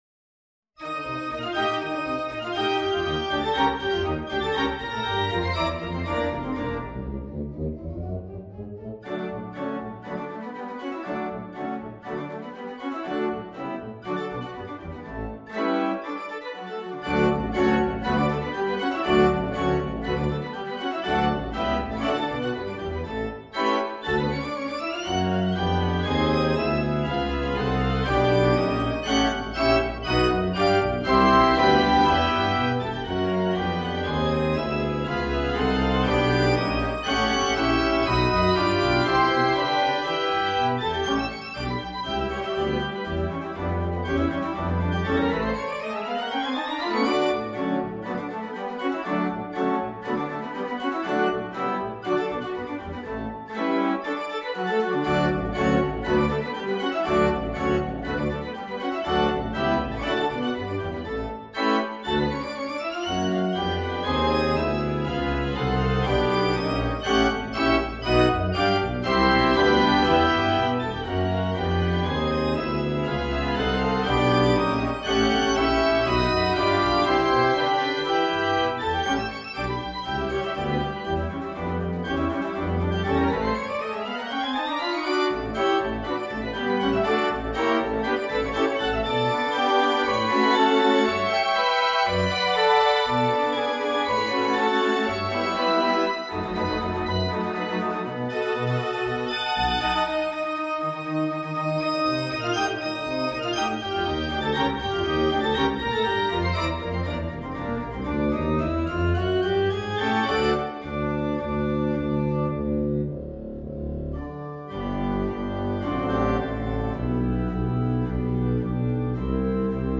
2/4 (View more 2/4 Music)
D major (Sounding Pitch) (View more D major Music for Organ )
Allegro con molto fuoco (View more music marked Allegro)
Organ  (View more Advanced Organ Music)
Classical (View more Classical Organ Music)
pomp-and-circumstance-march-1-op-39-1_ORG.mp3